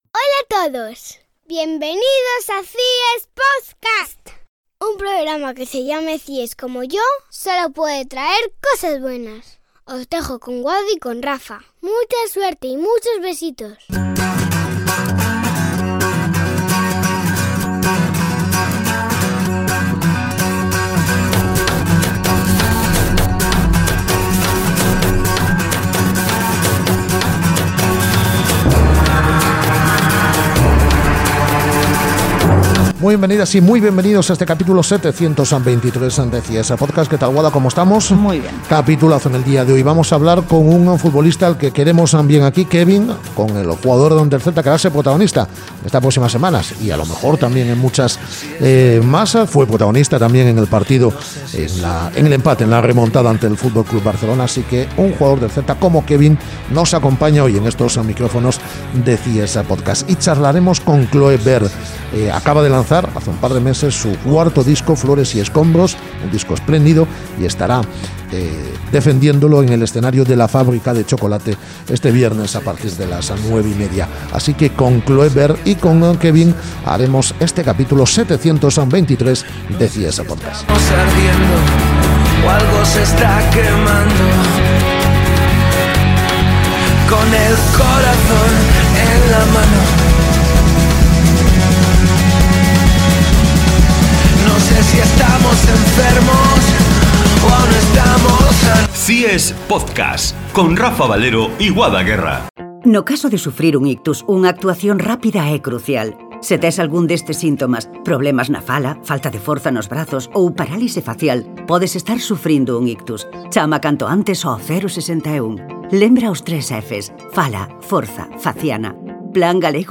Entrevista con el jugador del Celta